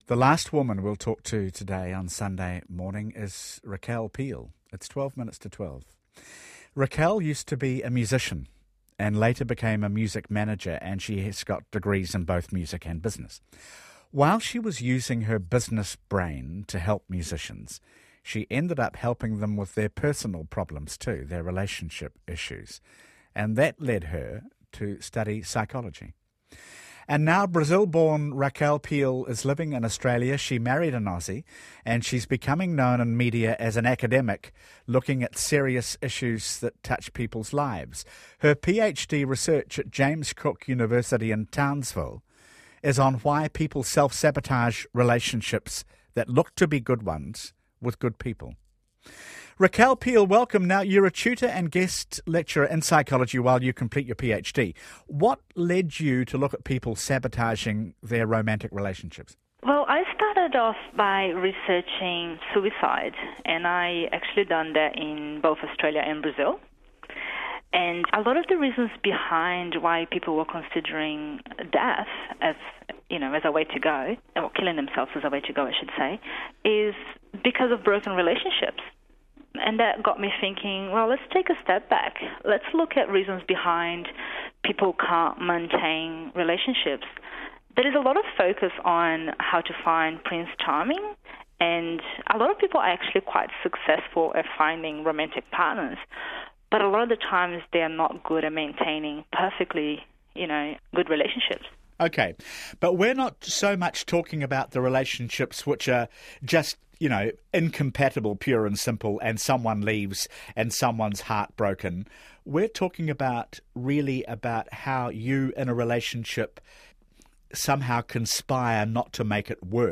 Retrieved from Radio New Zealand